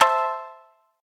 shamisen_cea.ogg